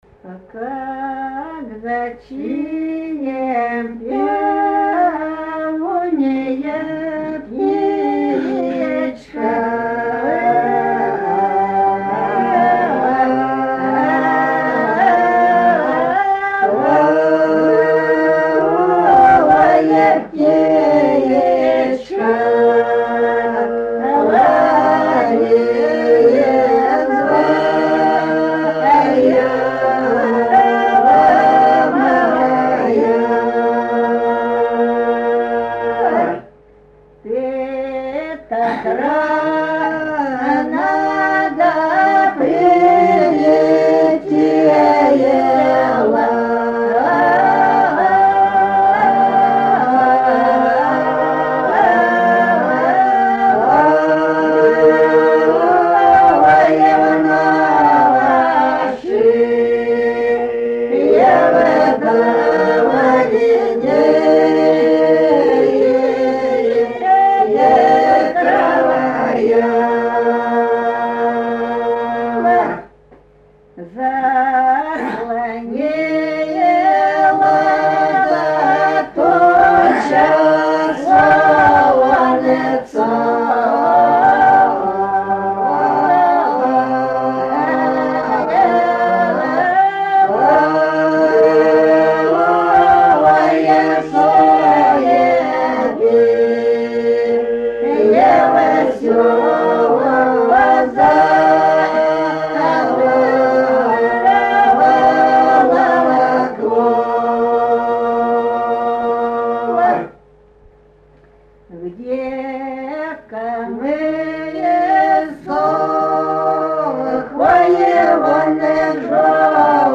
МУЗЫКАЛЬНАЯ КОЛЛЕКЦИЯ - ПОЮТ ДУХОБОРЦЫ
Духовные (Обрядовые) стихи "...Не унывай, душа..."
любительские записи (1977, 1988 гг.), с. Петровка Целинского р-на Ростовской области.